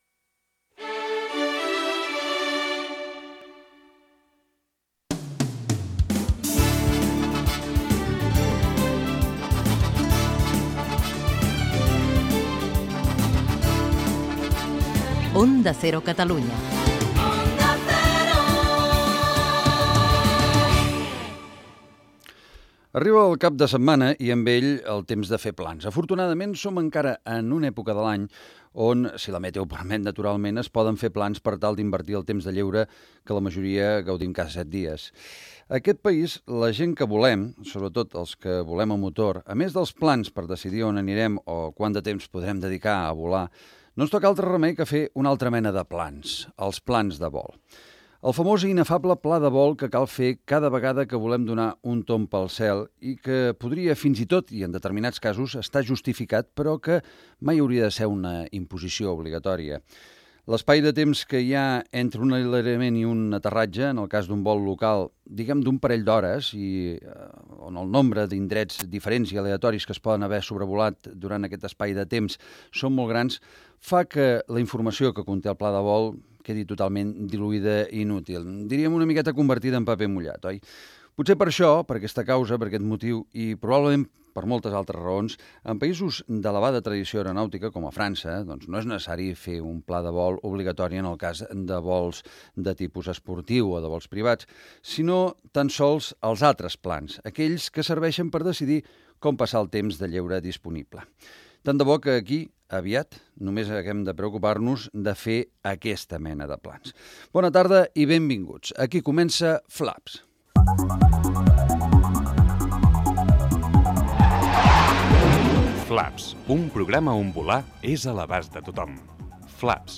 Indicatiu de la cadena, els plans de vol, sumari del programa, publicitat
Gènere radiofònic Divulgació